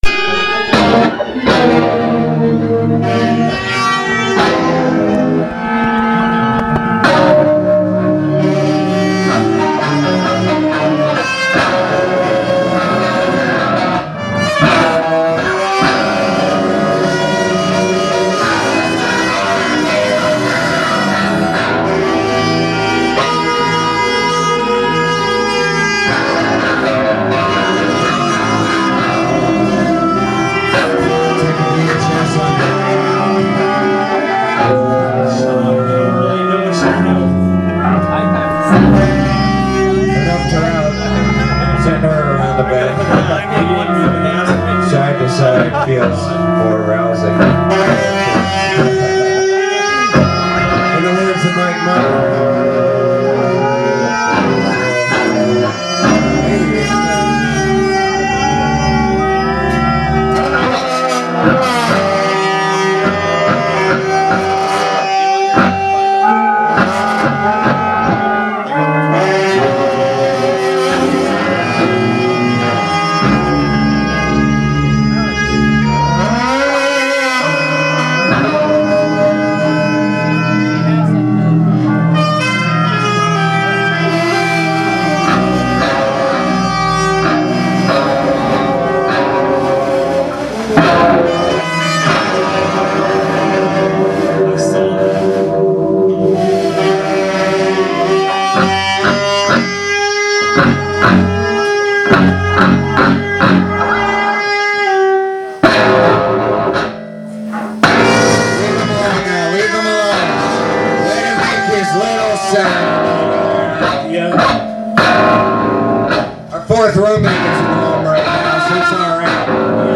noise jazz quartet
which is an independent music space in Hudson, NY.